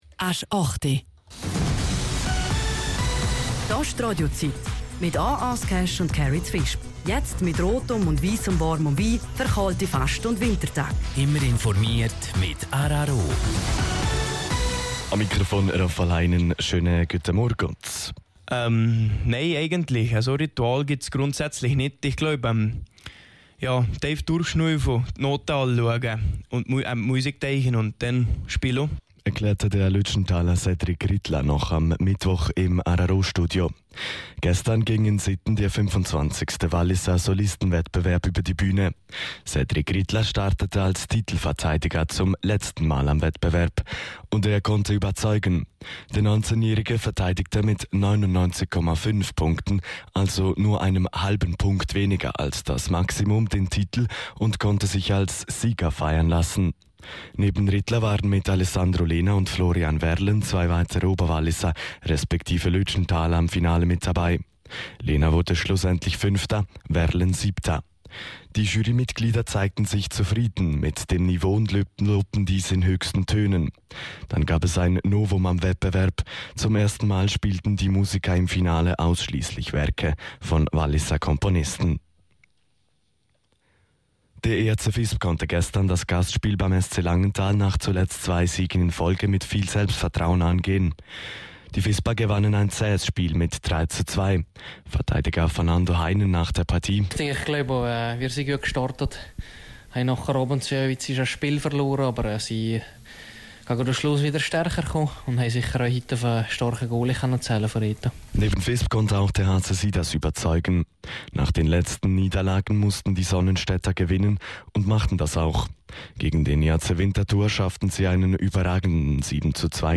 08:00 Nachrichten (5.13MB)